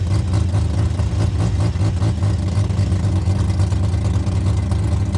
rr3-assets/files/.depot/audio/Vehicles/wr_01/wr_01_idle.wav
wr_01_idle.wav